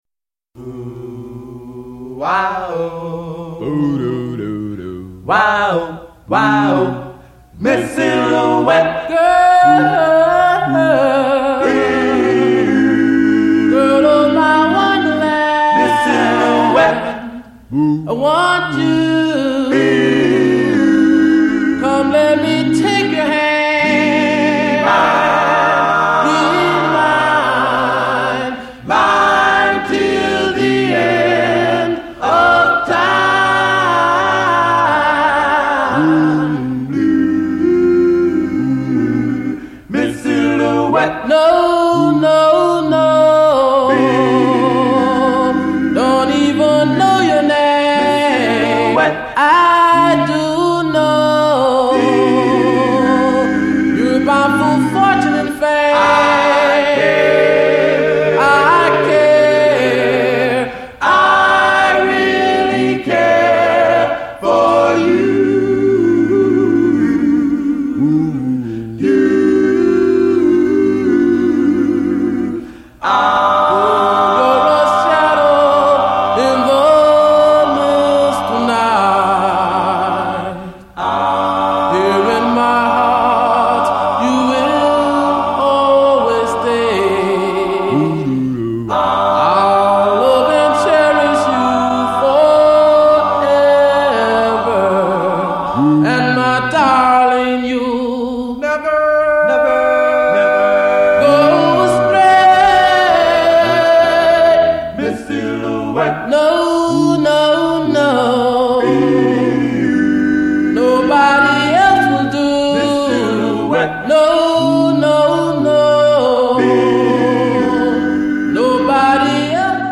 R&B / Doo Wop